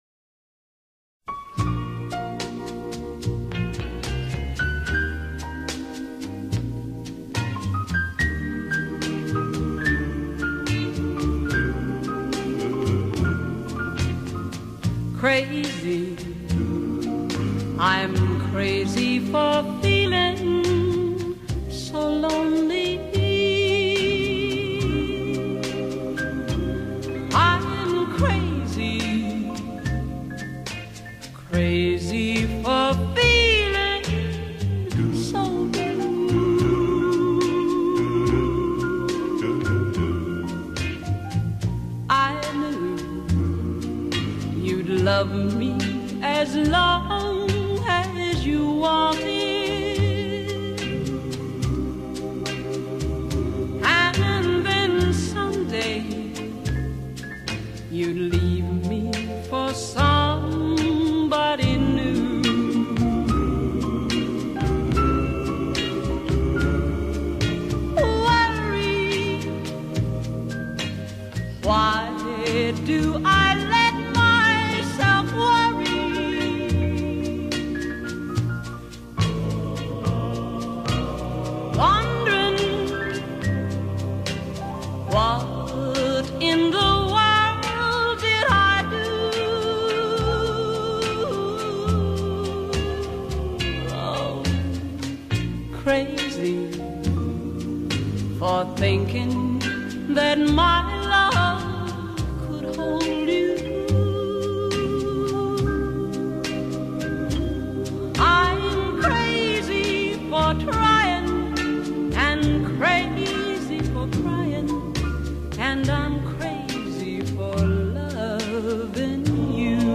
Country, Outlaw Country, Pop Country